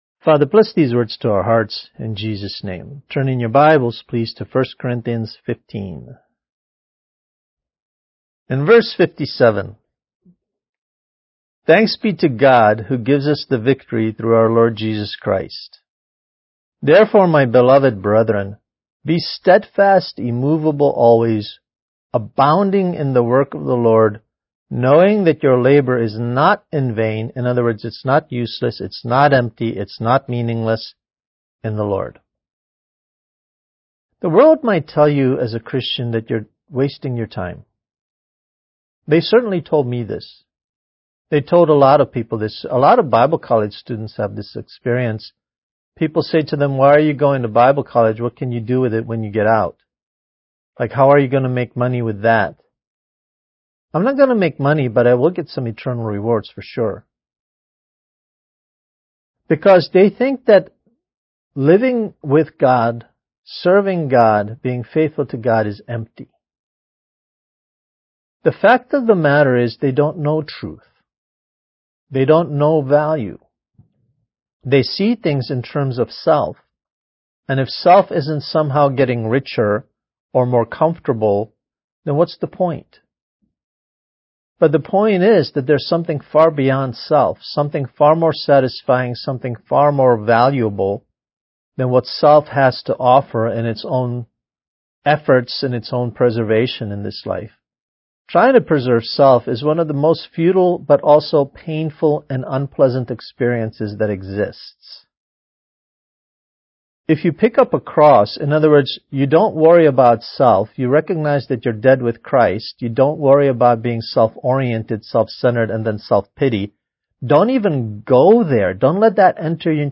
Kids Message: Finding Victory